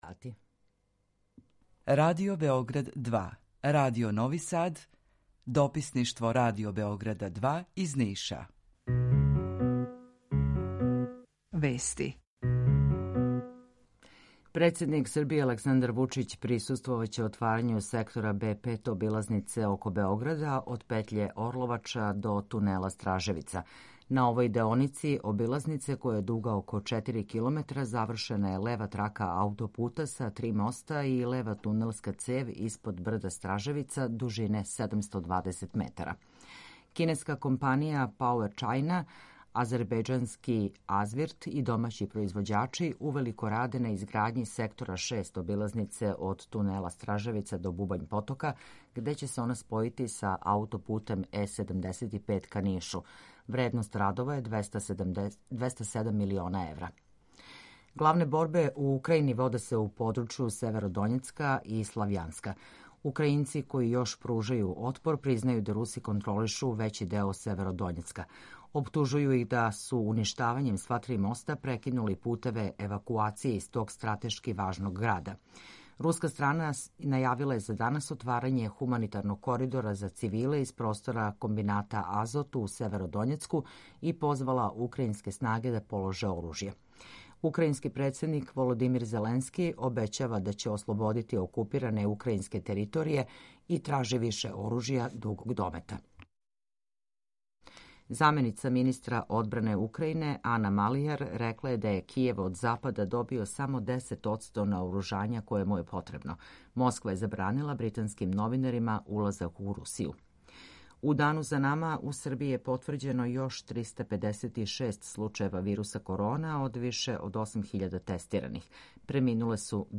Укључење Радио Грачанице
У два сата, ту је и добра музика, другачија у односу на остале радио-станице.